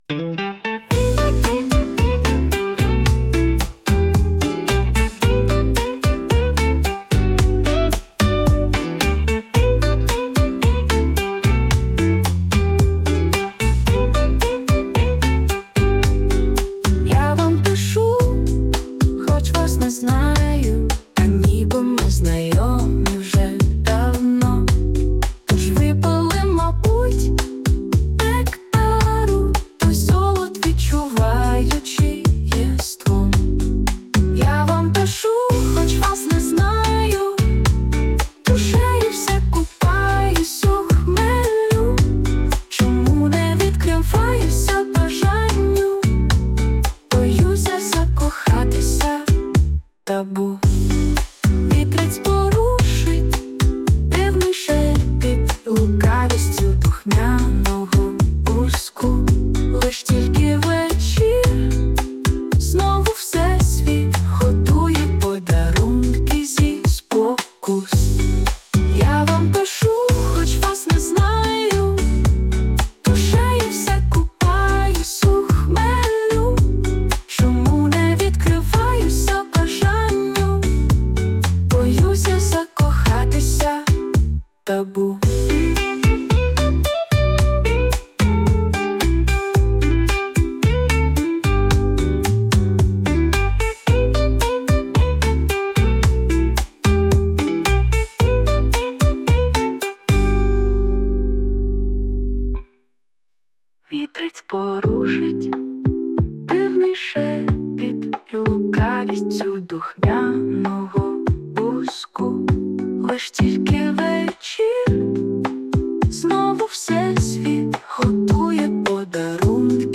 Музична композиція створена за допомогою SUNO AI
СТИЛЬОВІ ЖАНРИ: Ліричний